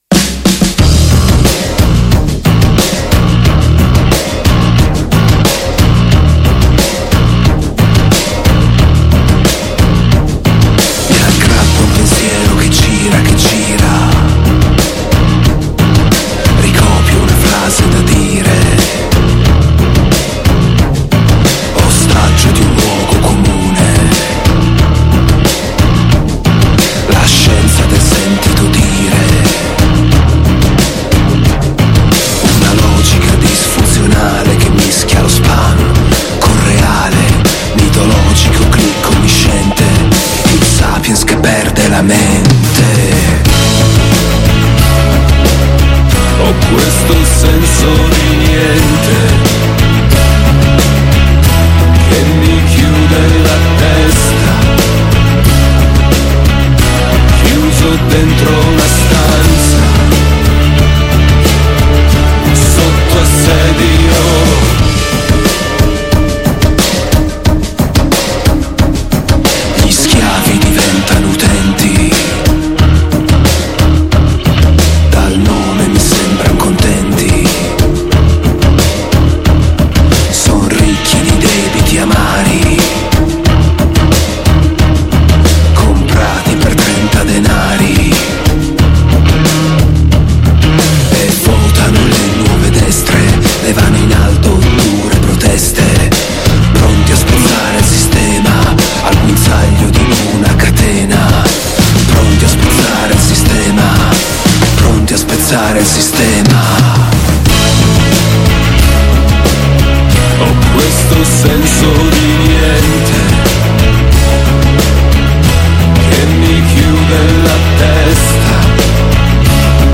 Intervista Ottobre Rosso | 23-1-23 | Radio Città Aperta